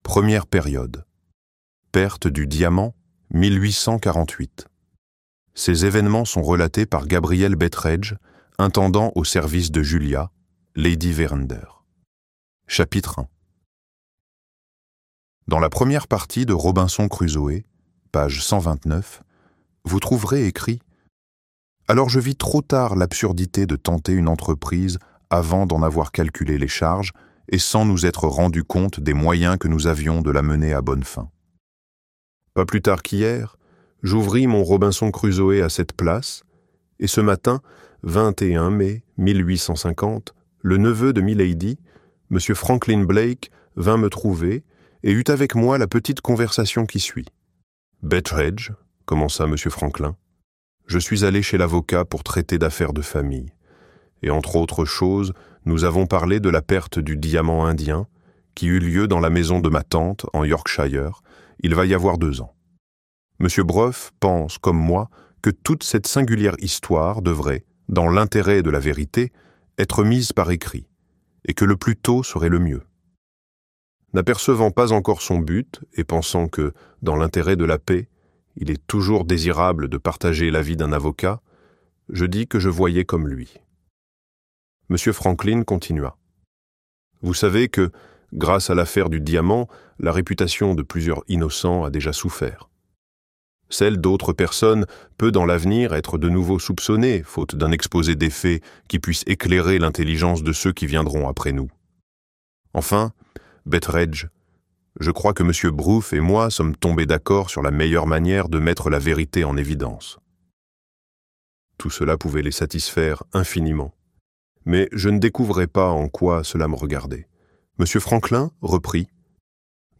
La Pierre de lune - Livre Audio